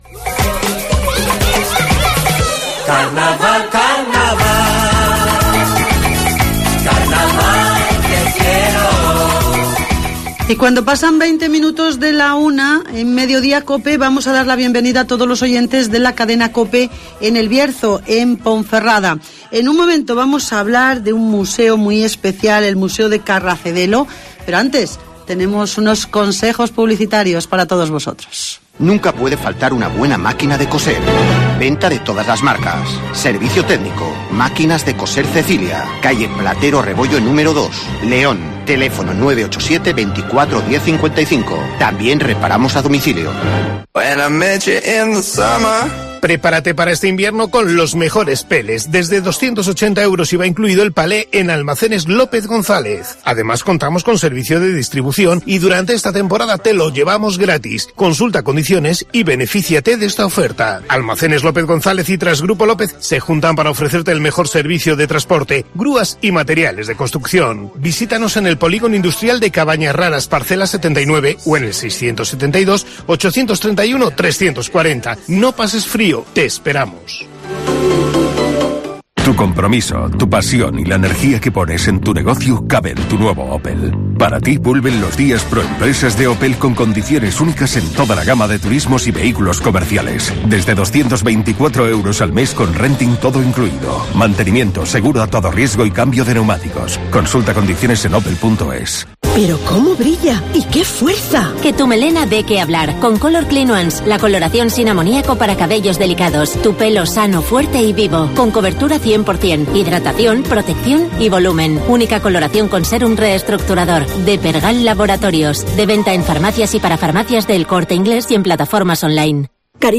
Hablamos del Museo Munic de Carracedelo (Entrevista